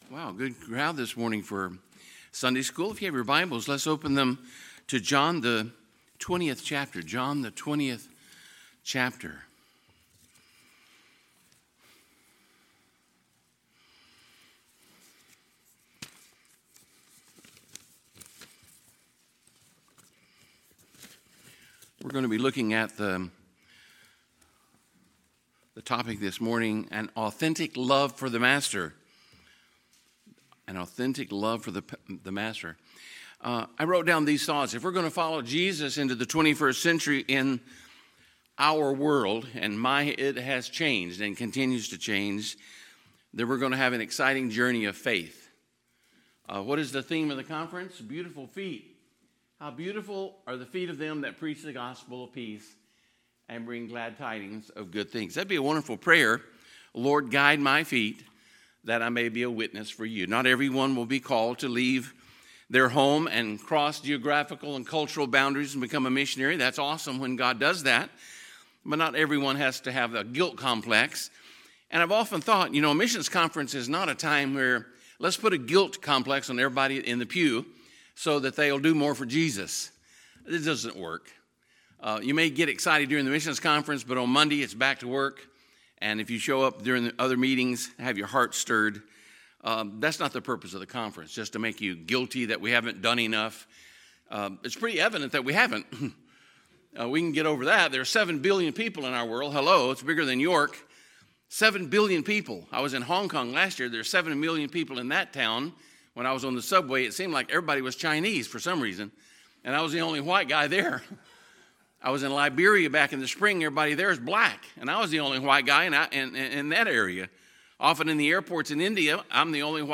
Sunday, September 23, 2018 – Sunday School Service